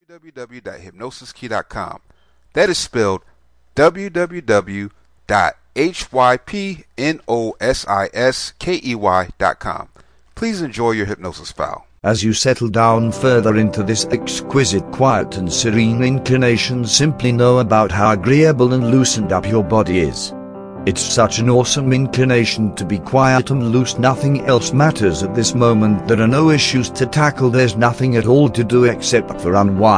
Placenta Previa Relaxation Self Hypnosis
Placenta Previa Relaxation Self Hypnosis Script Mp3, this is a powerful hypnosis script that helps placenta previa.